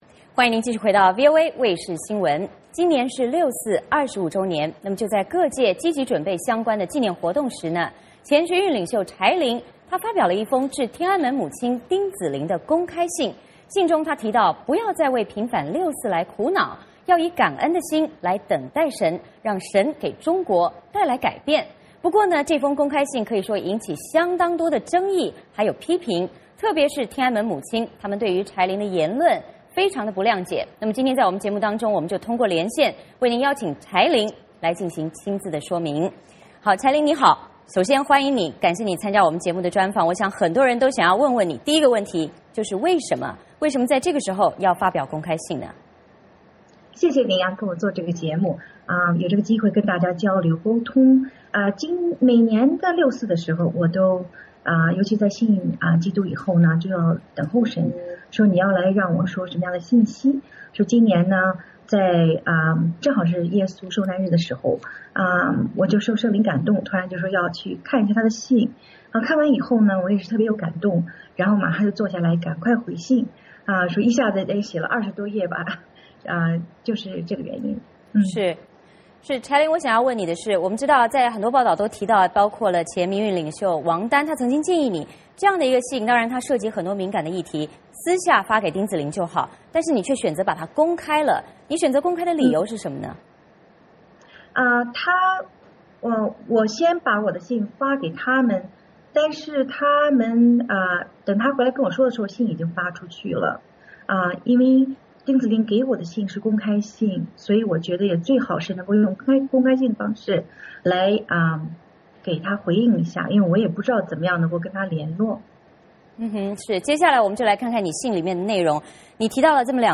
今天节目中，我们就为您邀请柴玲亲自说明。